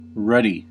Ääntäminen
Ääntäminen Tuntematon aksentti: IPA: [ˈpunɑinen] Haettu sana löytyi näillä lähdekielillä: suomi Käännös Ääninäyte Substantiivit 1. red US 2. color red Adjektiivit 3. red US 4.